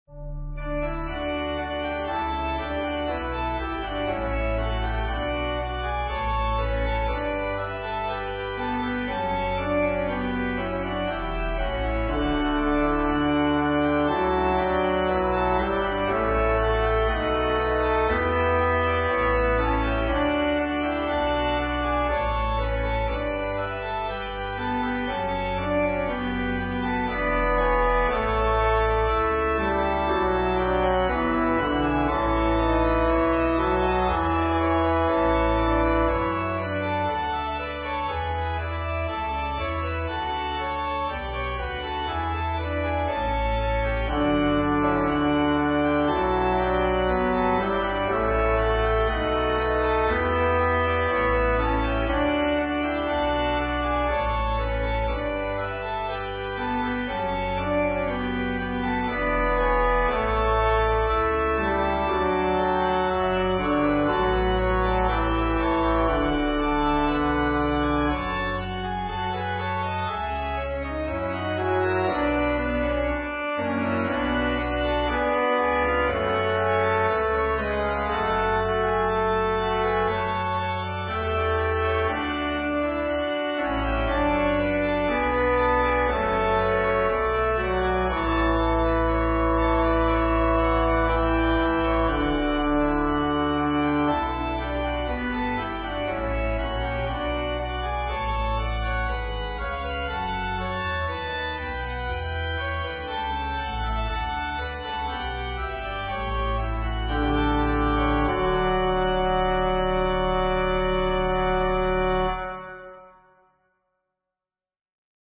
Organ/Organ Accompaniment
organ solo arrangement of this hymn.
Voicing/Instrumentation: Organ/Organ Accompaniment We also have other 38 arrangements of " Sweet Hour of Prayer ".